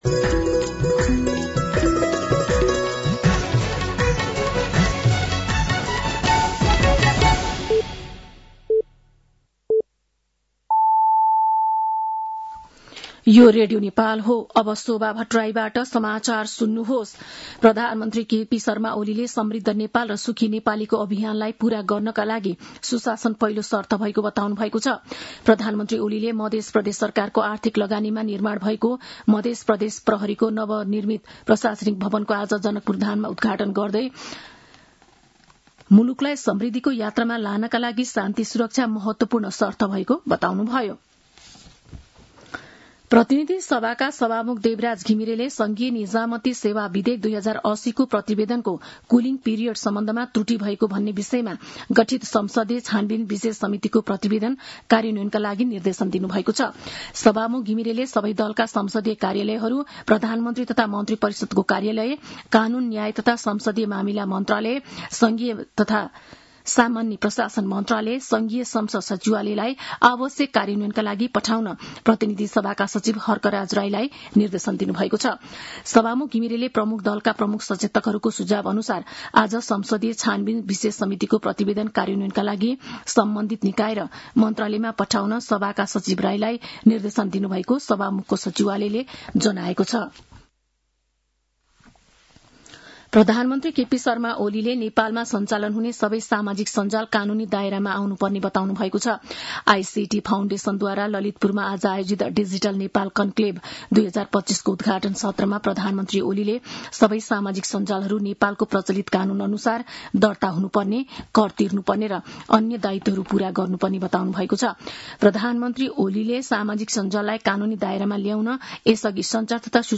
साँझ ५ बजेको नेपाली समाचार : ३० साउन , २०८२